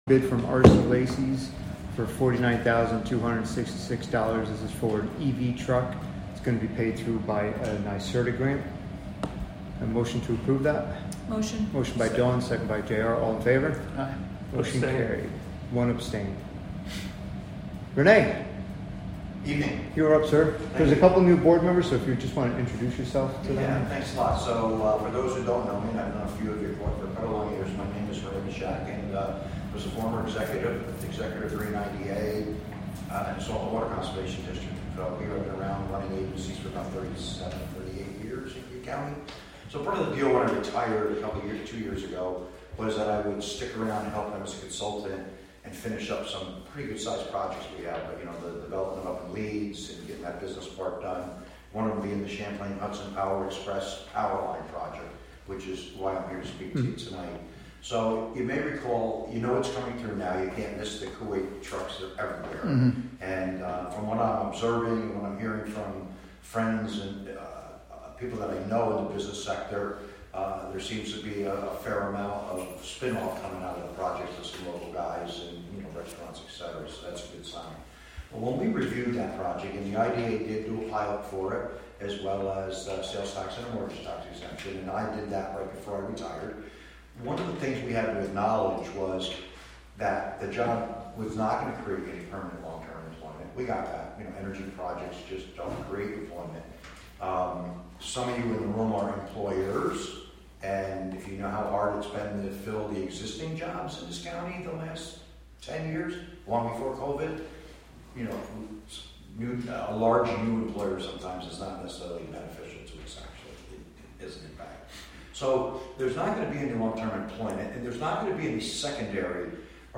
Live from the Town of Catskill: June 18, 2024 Catskill Town Board Meeting (Audio)